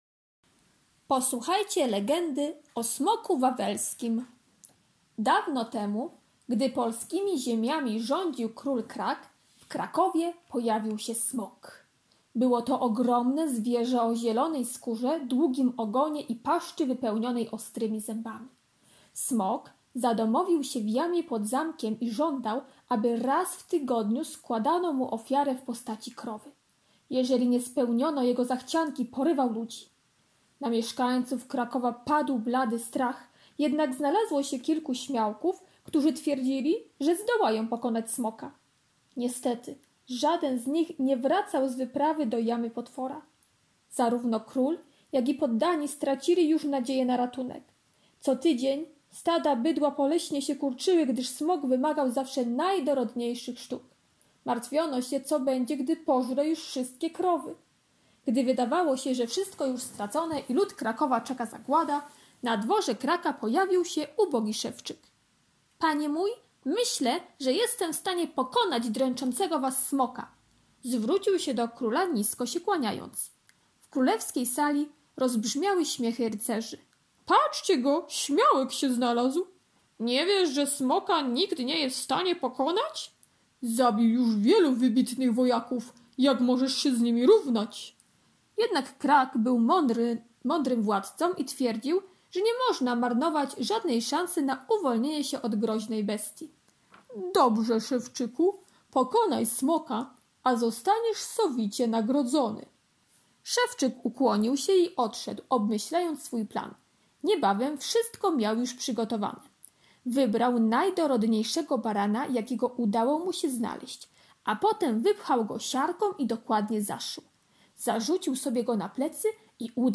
2) Prosimy, żeby dziecko posłuchało legendy pt. "O smoku wawelskim" czytanej przez nauczyciela (plik mp3 znajduje się w załączniku).